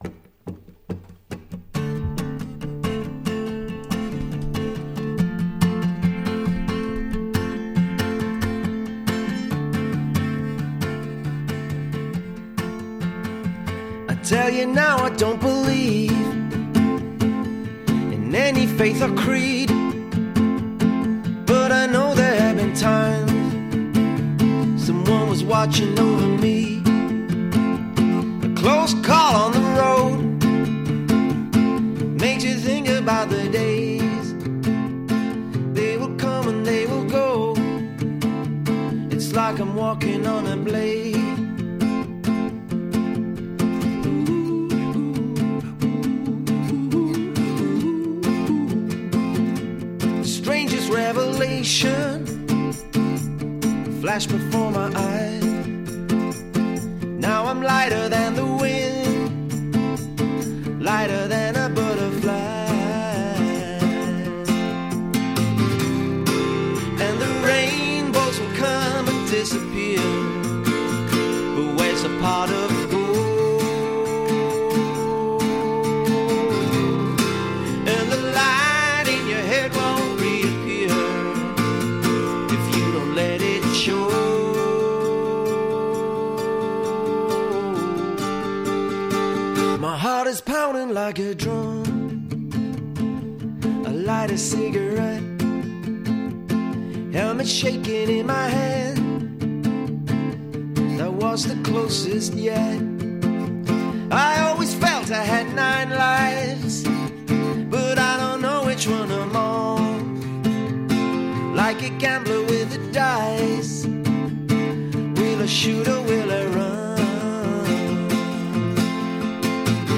Studio Session